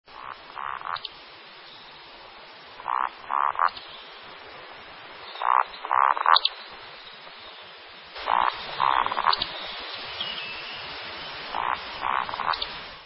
Au crépuscule, le mâle survole son territoire en chantant (on dit qu’il « croule »).
becassedesbois01.mp3